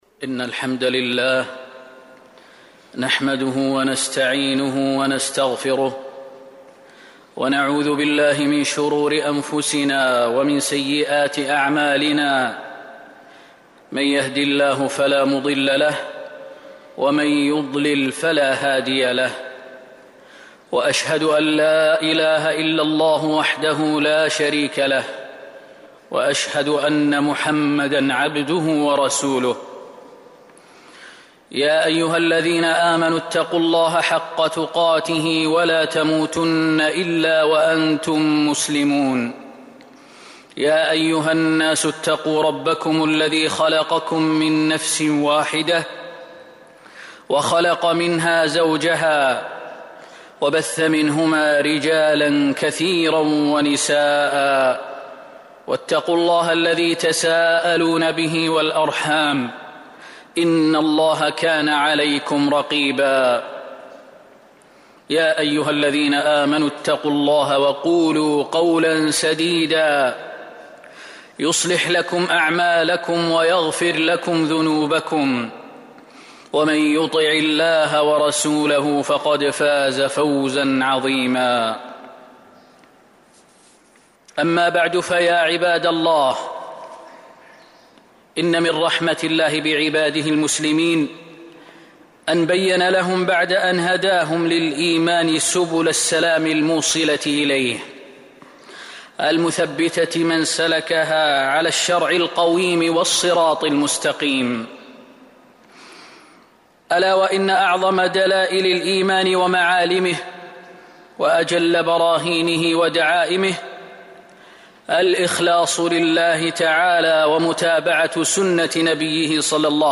جودة عالية. التصنيف: خطب الجمعة